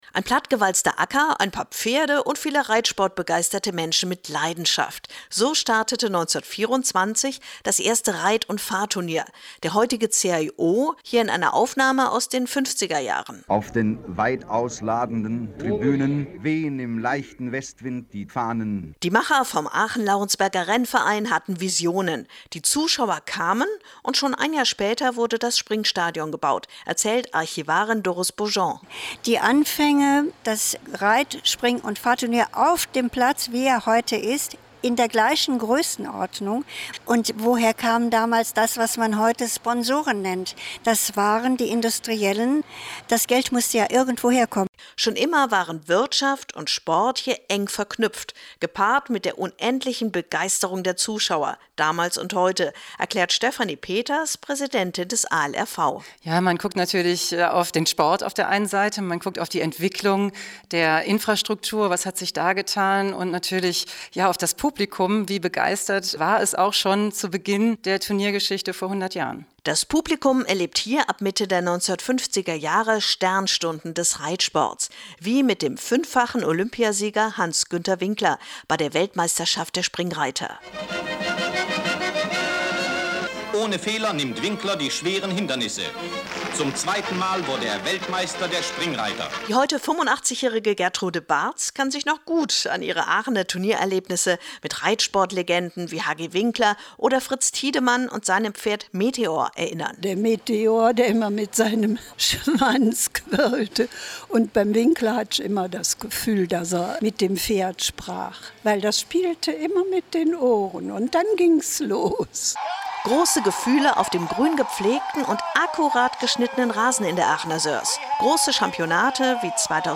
ARD: Hörfunkbeitrag 100 Jahre Turniergeschichte